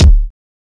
Wu-RZA-Kick 48.wav